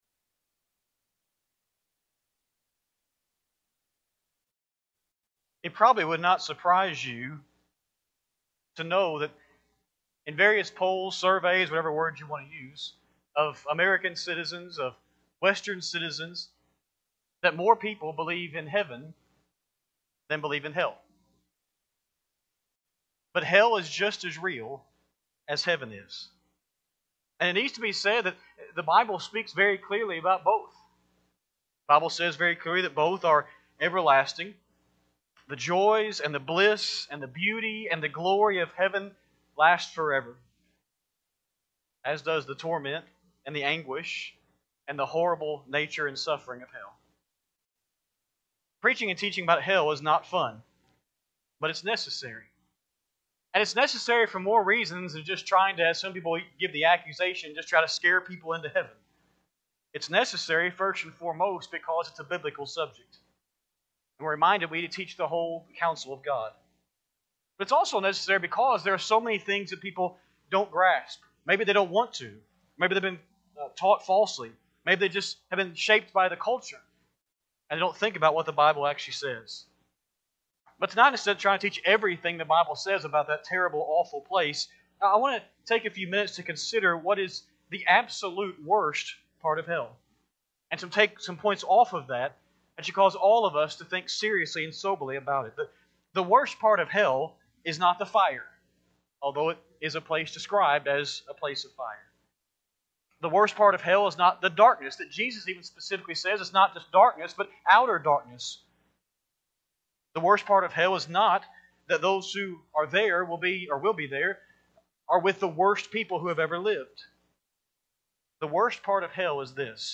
3-22-26-Sunday-PM-Sermon.mp3